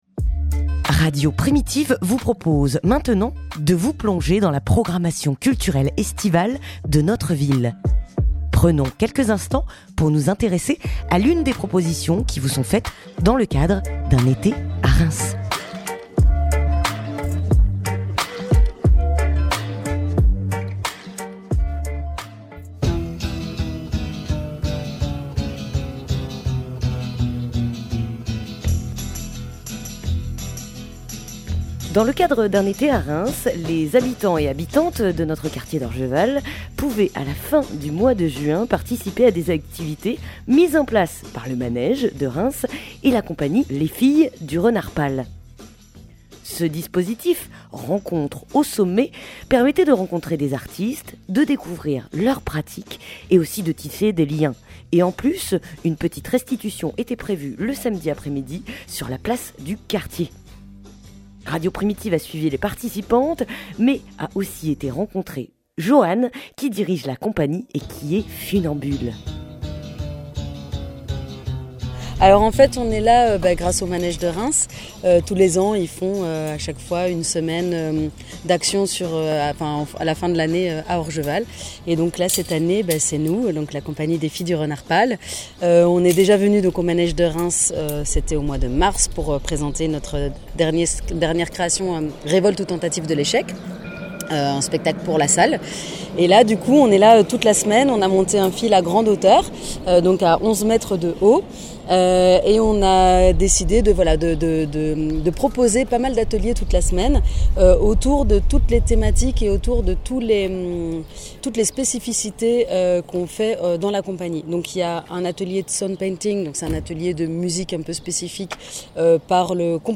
Reportage à Orgeval (14:44)